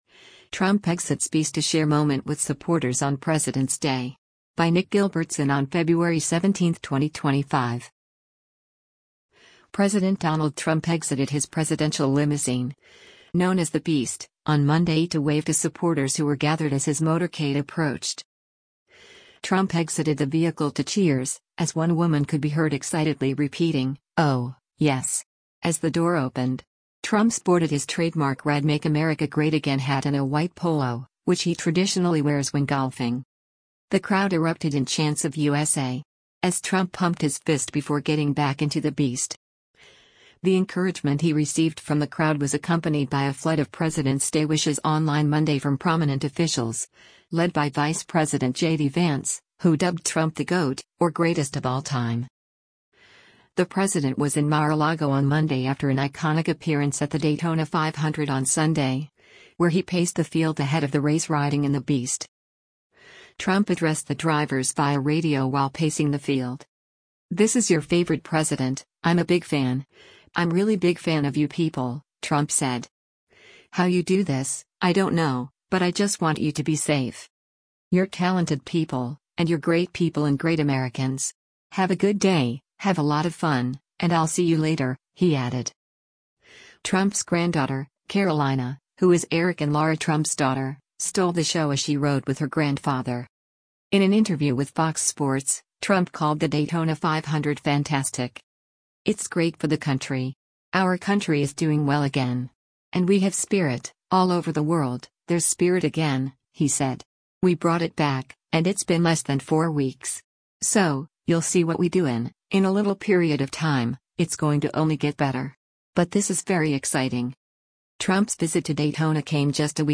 Trump exited the vehicle to cheers, as one woman could be heard excitedly repeating, “Oh, yes!” as the door opened.
The crowd erupted in chants of “USA!” as Trump pumped his fist before getting back into the Beast.